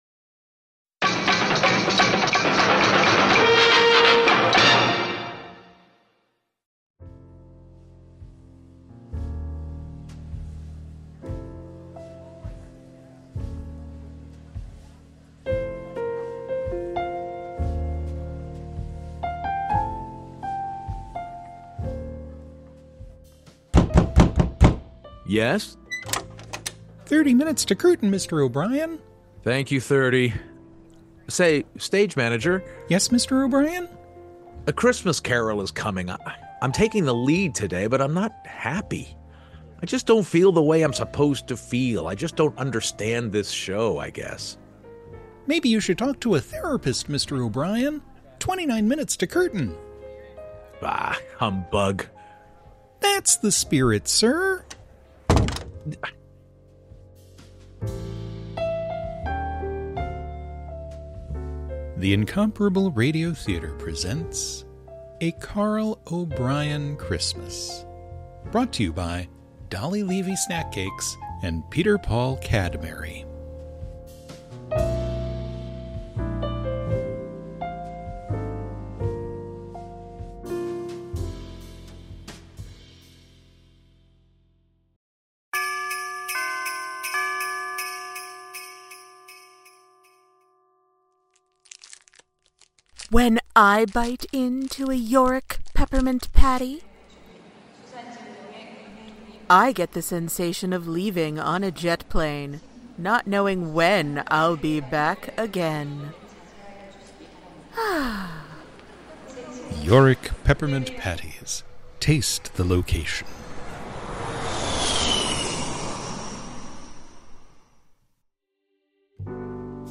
A Carl O'Brien Christmas (Incomparable Radio Theater 4.3)